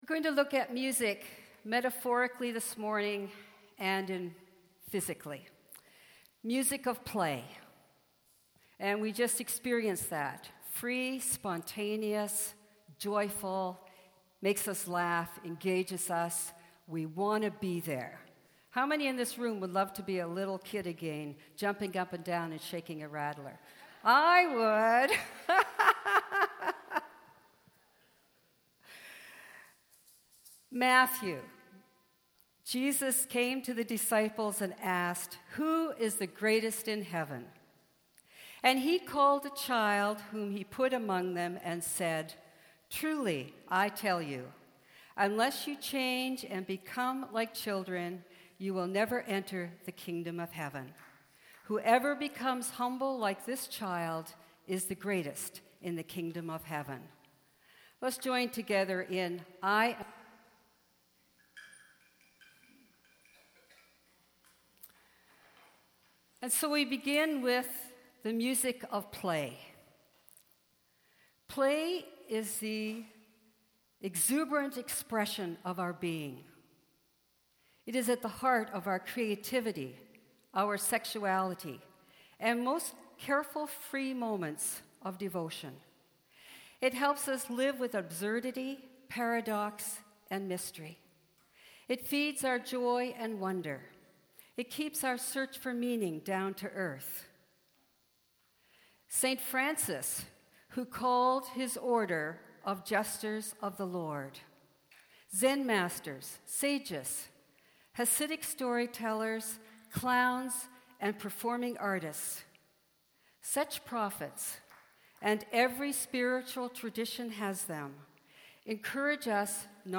Sermons | Knox United Church